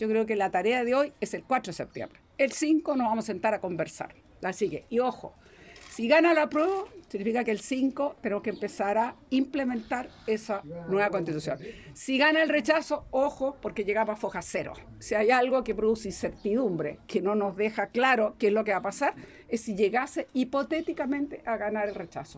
También estuvo presente en esta instancia la senadora y expresidenta del Partido Socialista, Isabel Allende, quien también se sumó a la campaña de despliegue por el Apruebo.